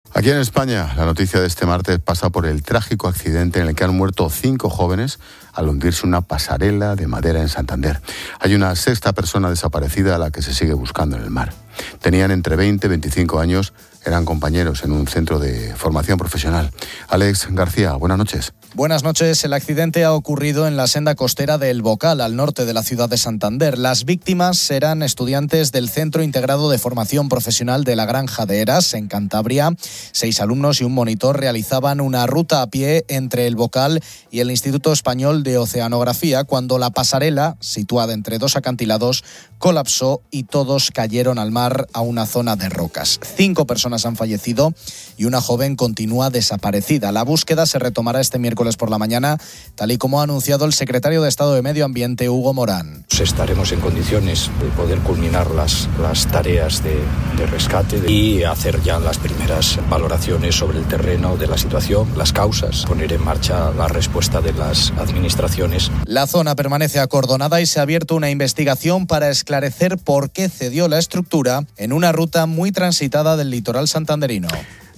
Expósito conoce con el de COPE Cantabria